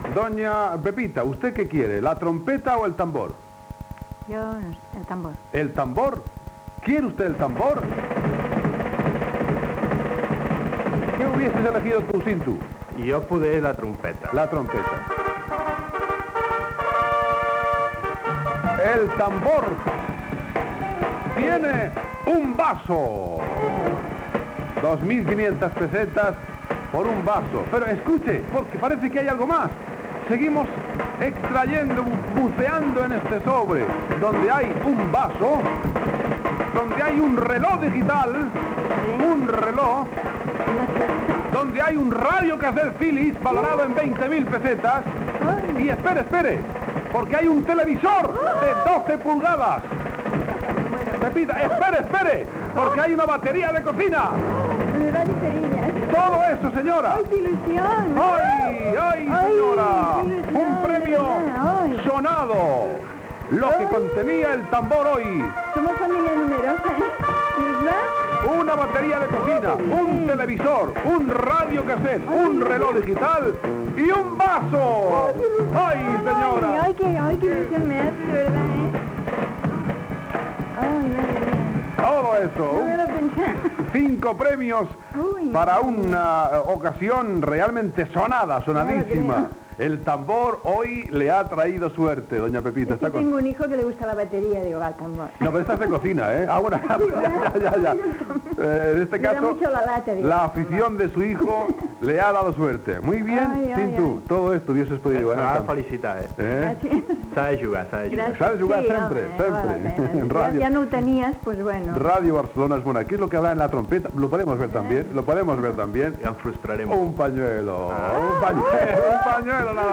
La concursant escull el sobre que vol i guanya diversos premis.